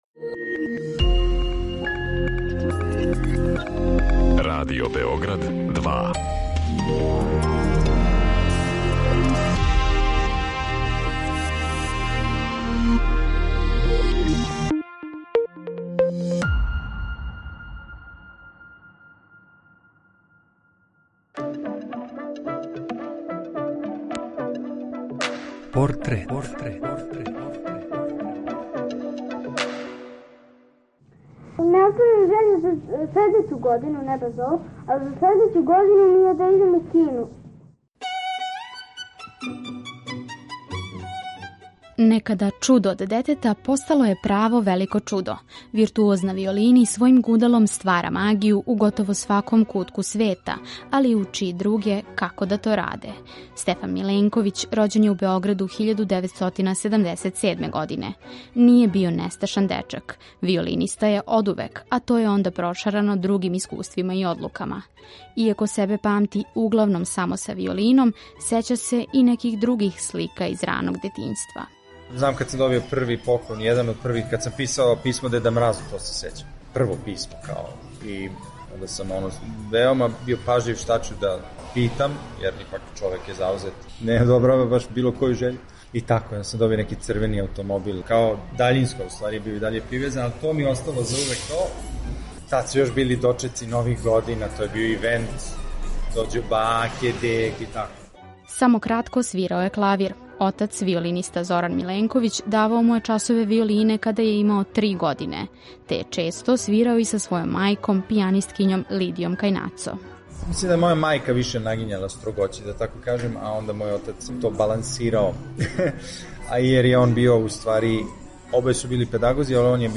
Приче о ствараоцима, њиховим животима и делима испричане у новом креативном концепту, суптилним радиофонским ткањем сачињеним од: интервјуа, изјава, анкета и документраног материјала. О портретисаним личностима говоре њихови пријатељи, најближи сарадници, истомишљеници…